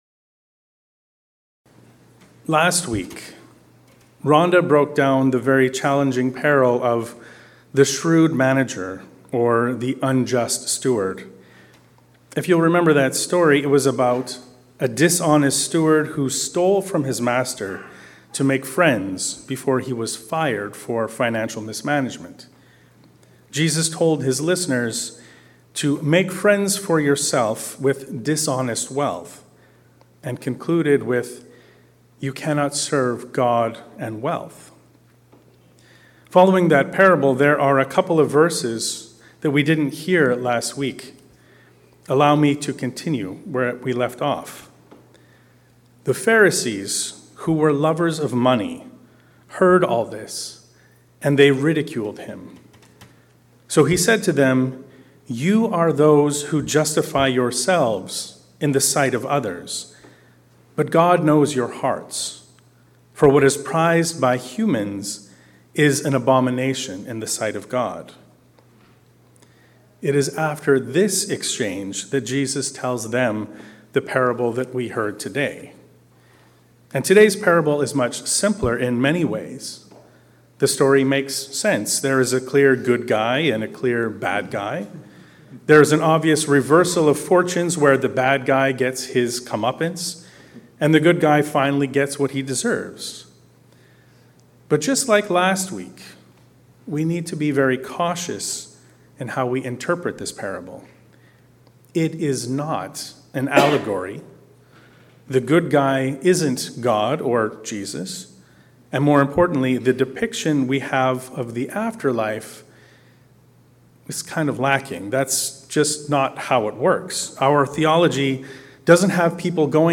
Recognizing the Issue on Our Doorstep. A sermon on Luke 16.19-31